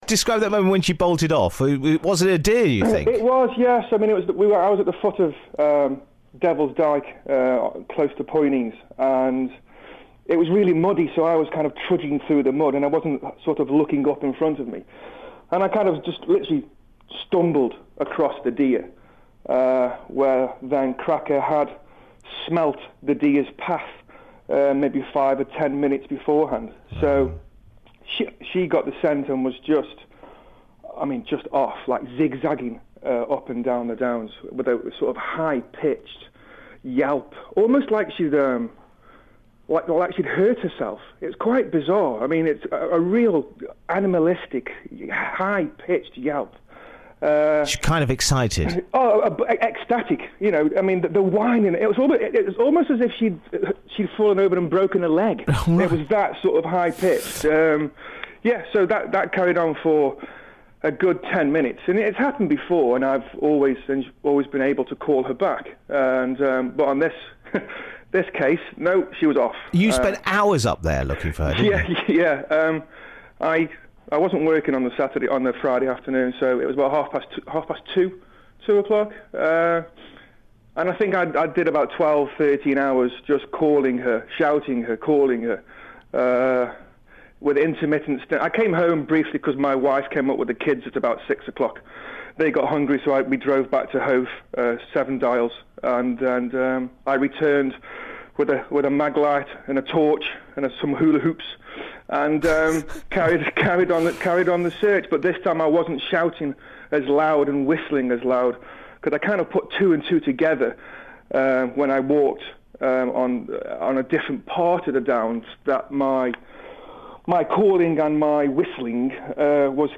on BBC Sussex this morning